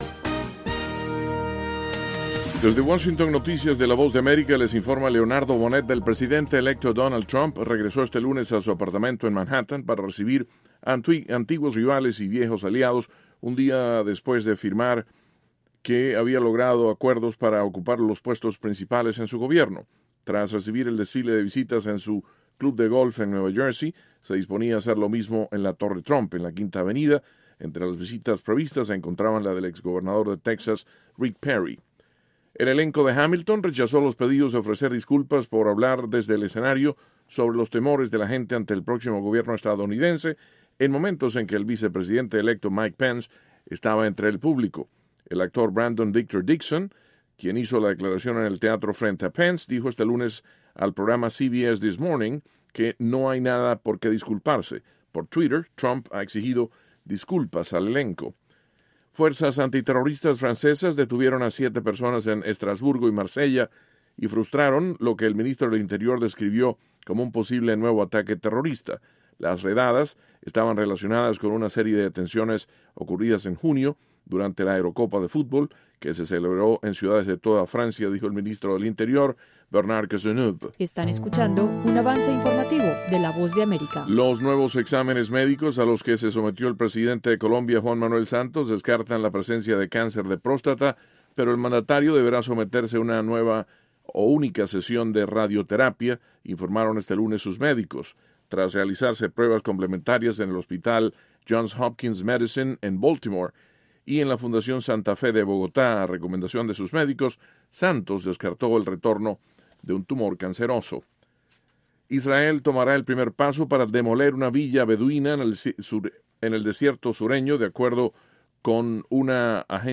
Capsula informativa de tres minutos con el acontecer noticioso de Estados Unidos y el mundo.